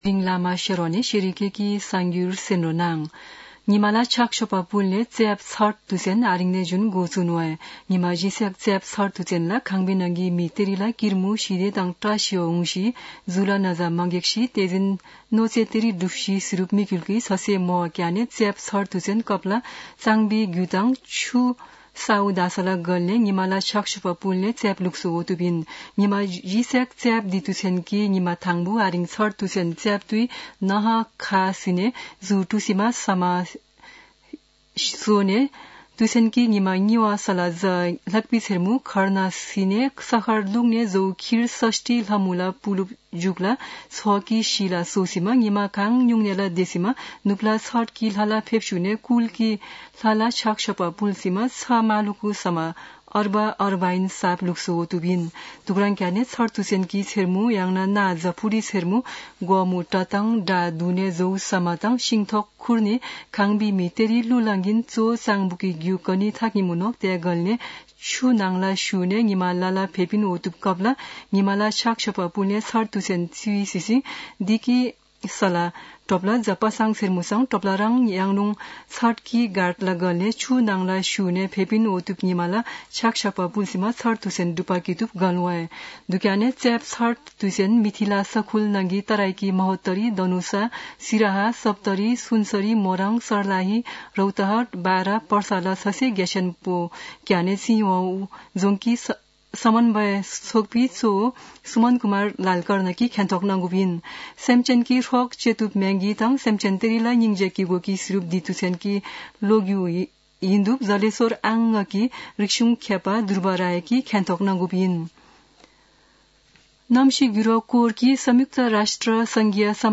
शेर्पा भाषाको समाचार : २१ कार्तिक , २०८१
Sherpa-News-20.mp3